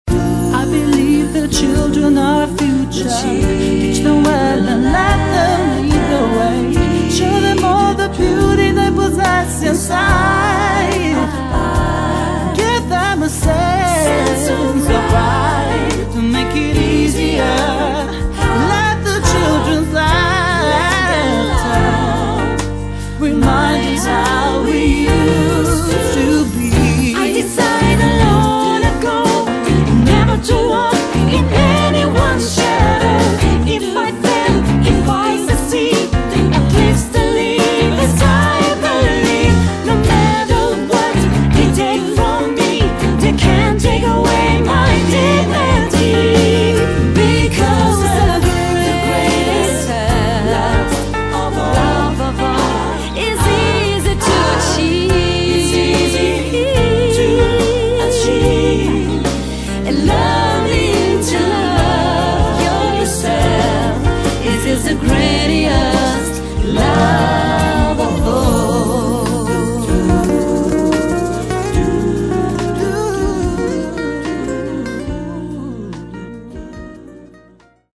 La cover-song
formazione vocale di stampo soul/jazz/pop.